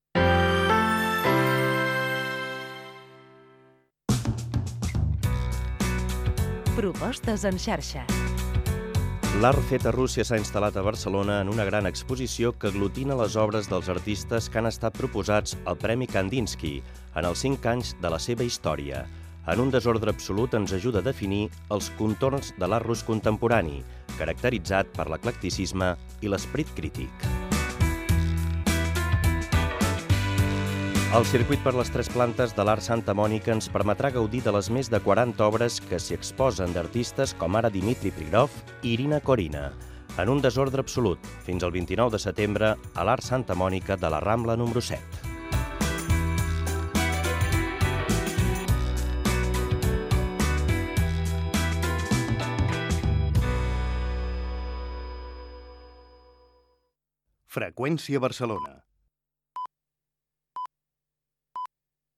Senyal desconnexió, espai de propostes culturals a Barcelona, indicatiu i senyals horaris
FM